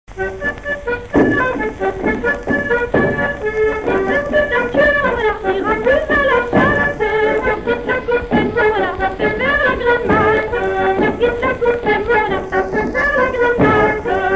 Divertissements d'adultes - Couplets à danser
branle : courante, maraîchine
Pièce musicale inédite